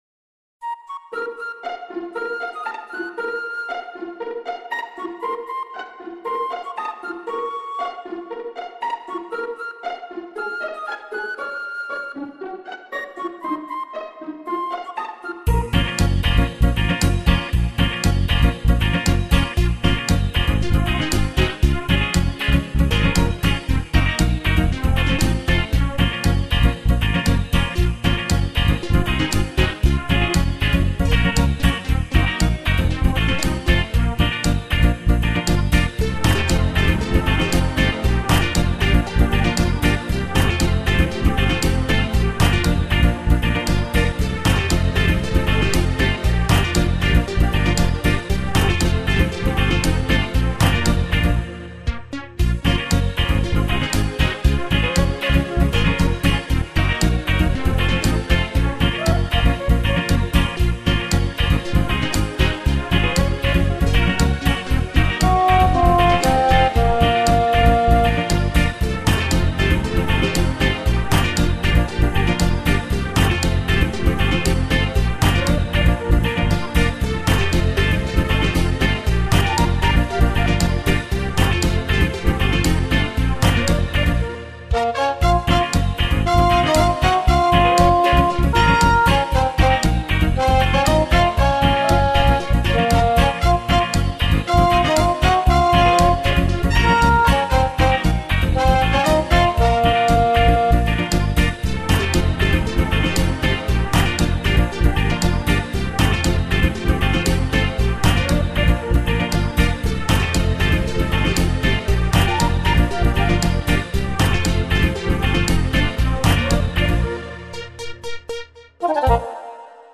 мелодия без слов